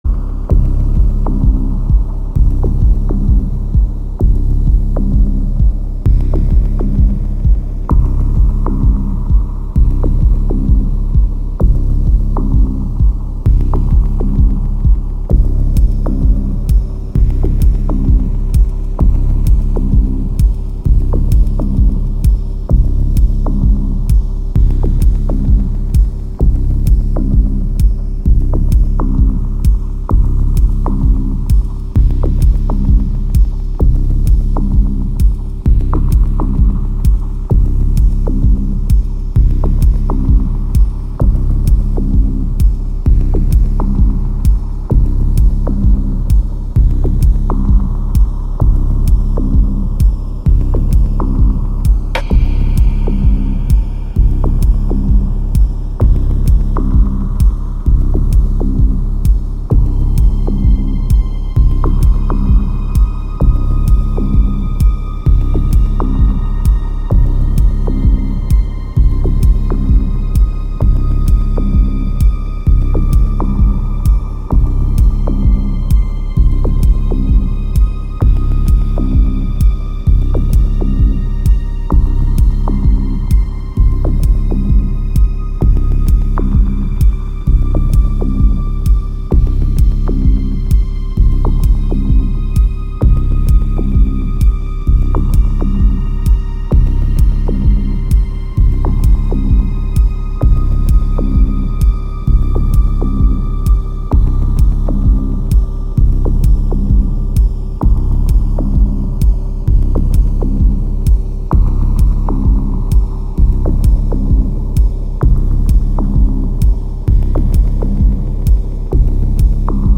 Radio station
Electronic, Techno, House, Deep, Dub, Jazz, R&B, Hip Hop, Smooth, Ambient